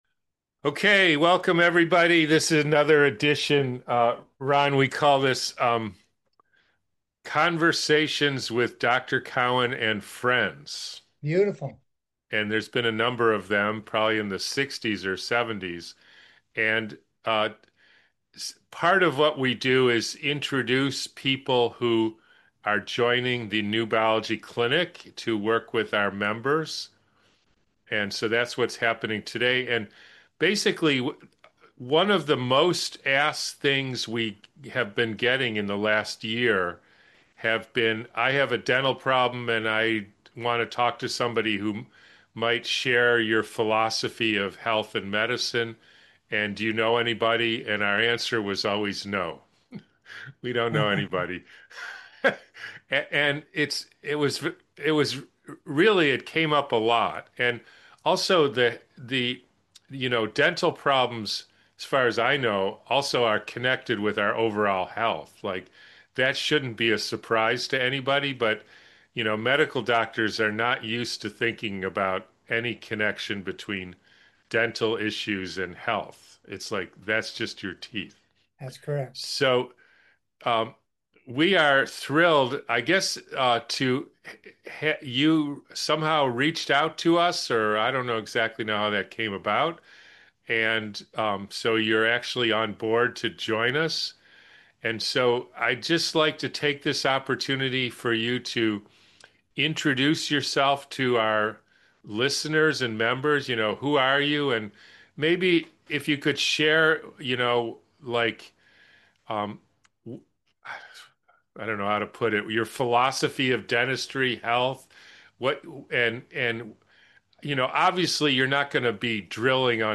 In this conversation, a dentist discusses his journey from traditional dentistry to biological dentistry. He explains how he began to see a connection between dental health and overall health, which led him to question common practices like root canals.